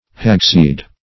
Hagseed \Hag"seed`\ (h[a^]g"s[=e]d)